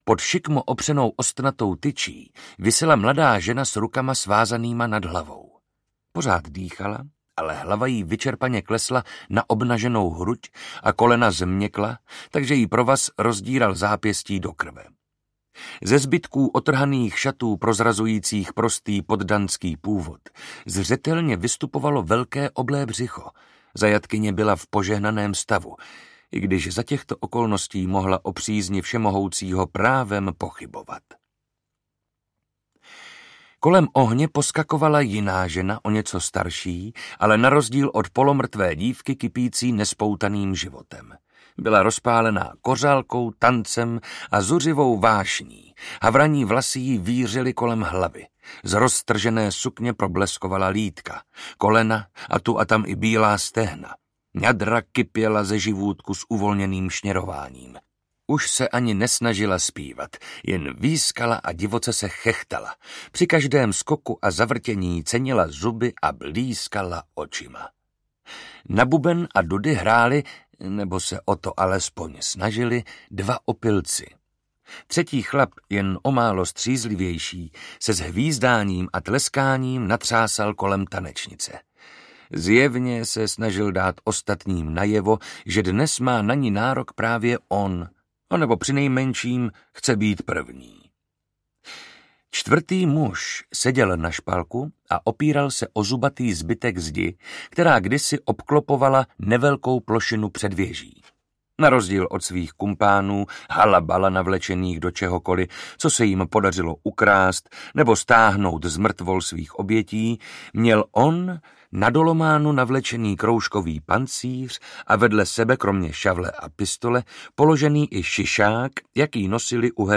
Pro hrst dukátů audiokniha
Ukázka z knihy
Čte Marek Holý.
Vyrobilo studio Soundguru.